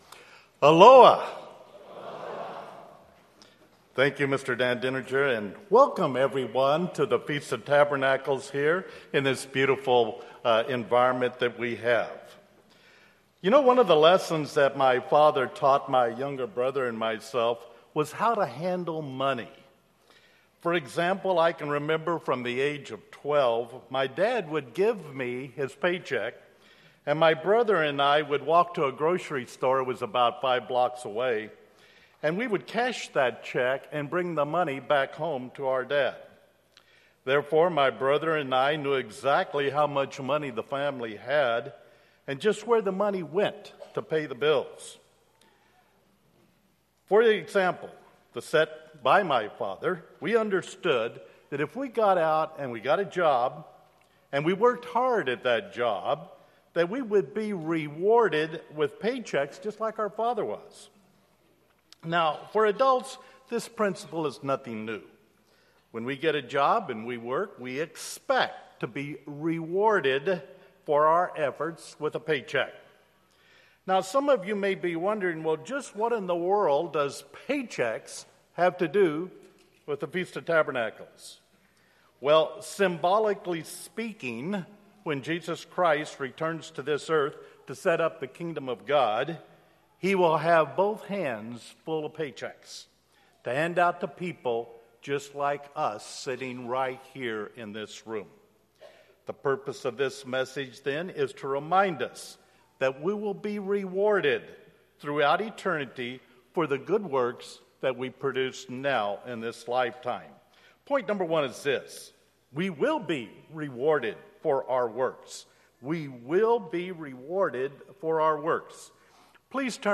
This sermon was given at the Lihue, Hawaii 2014 Feast site.